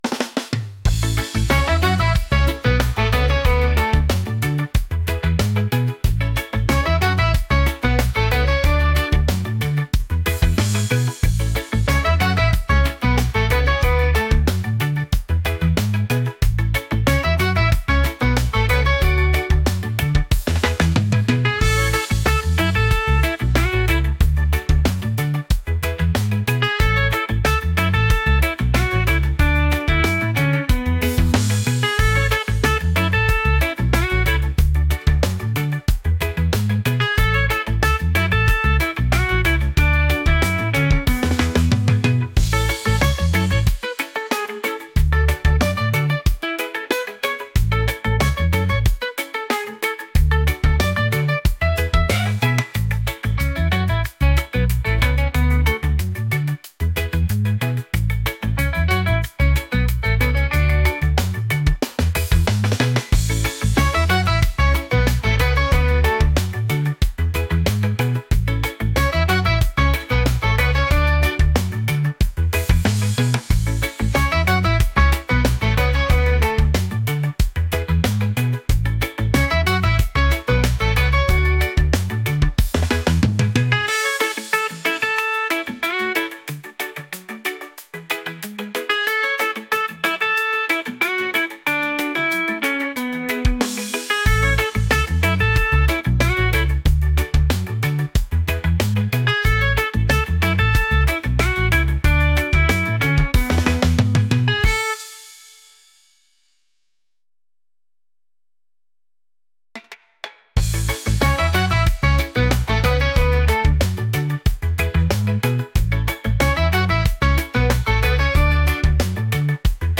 upbeat | reggae | catchy